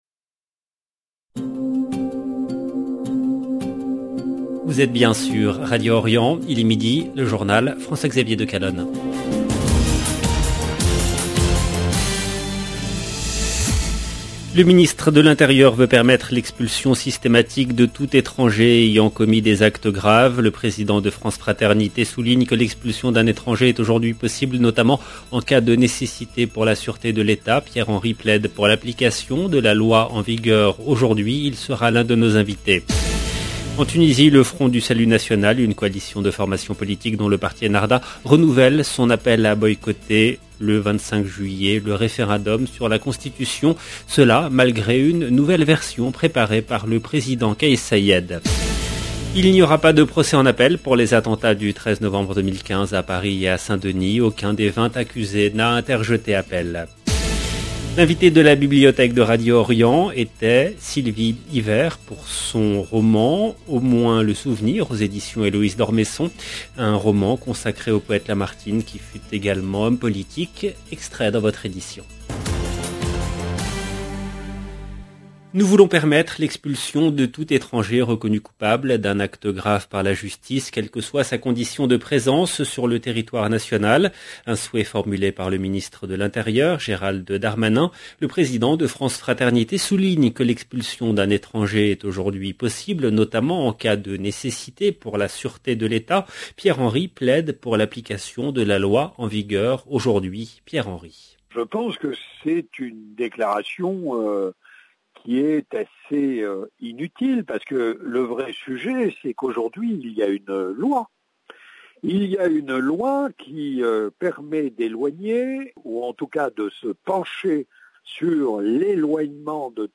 Il sera l’un de nos invités.